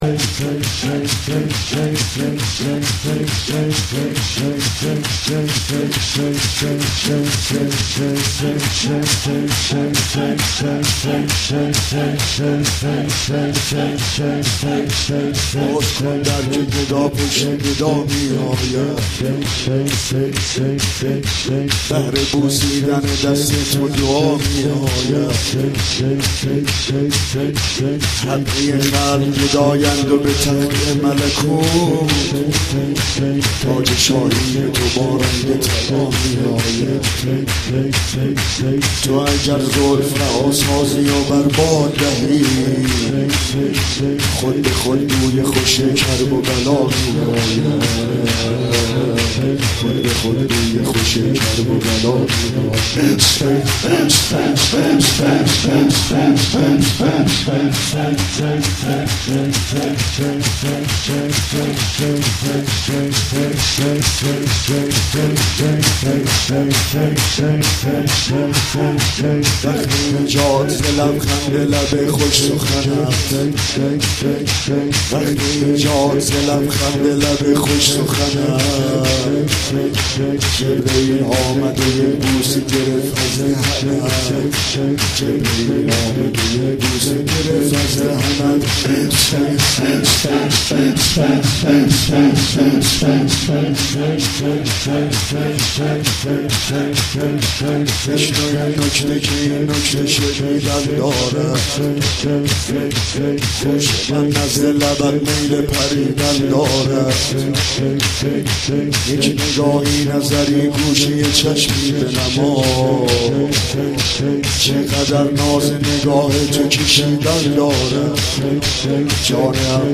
کلچین مولودی ولادت حضرت علی اکبر (ع) حاج عبدالرضا هلالی